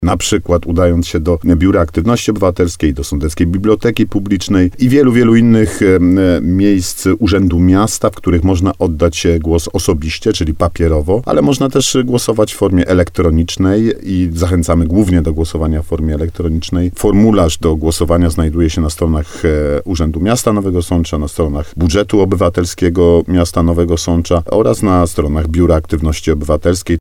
– Głosować można na różne sposoby – zachęca Artur Bochenek, zastępca prezydenta Nowego Sącza.